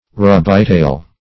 Search Result for " rubytail" : The Collaborative International Dictionary of English v.0.48: Rubytail \Ru"by*tail`\, n. (Zool.)